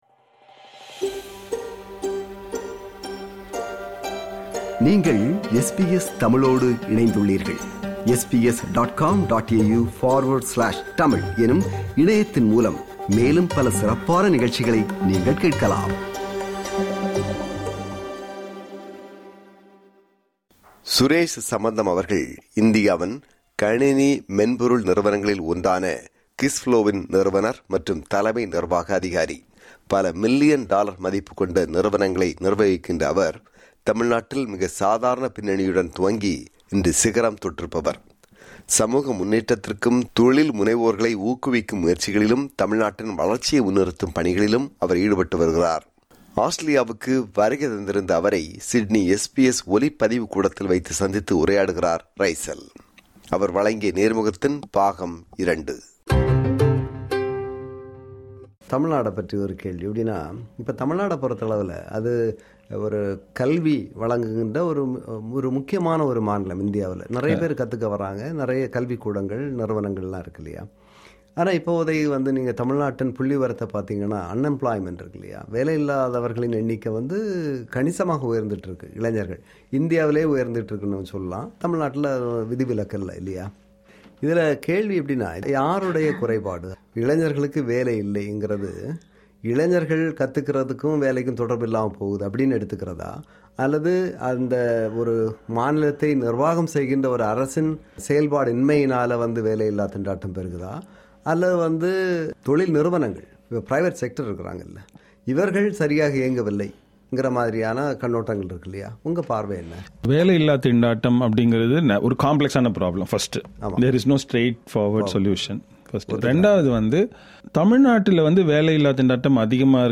ஆஸ்திரேலியாவுக்கு வருகை தந்திருந்த அவரை சிட்னி SBS ஒலிப்பதிவு கூடத்தில் வைத்து
நேர்முகம் பாகம் 2